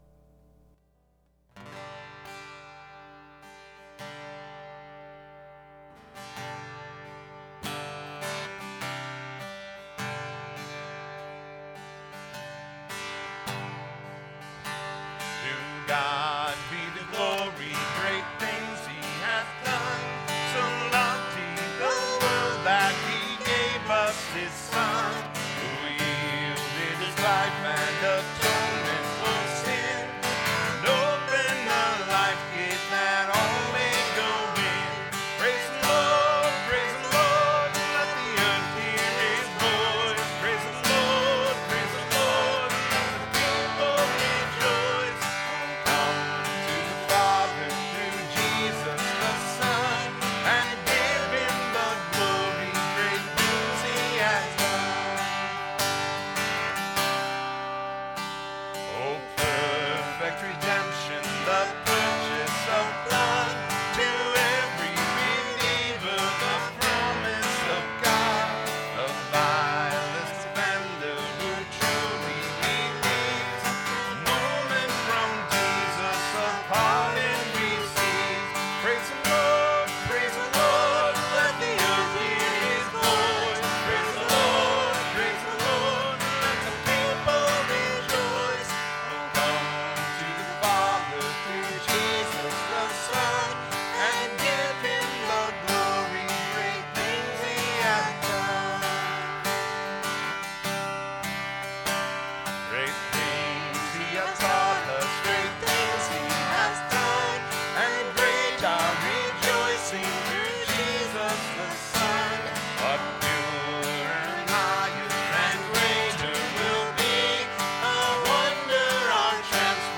Weekly Sermons - Evangelical Free Church of Windsor, CO